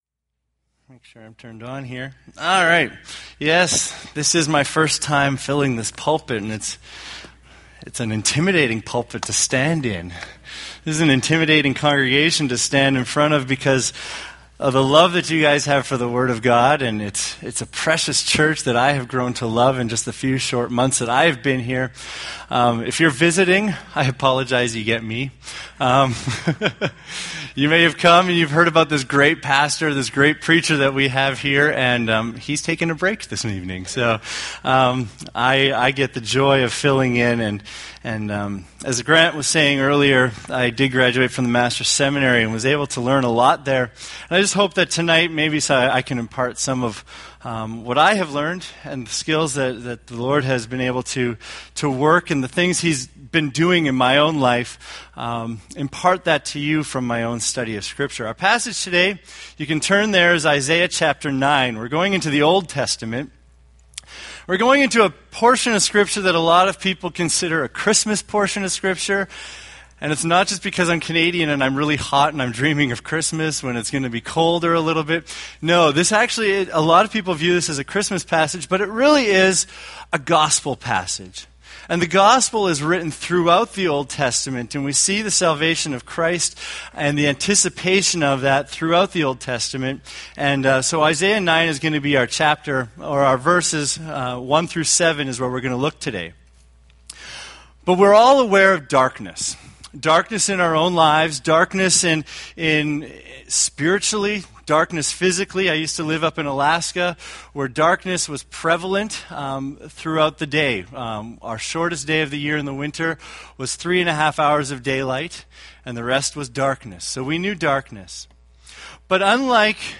Isaiah Sermon Series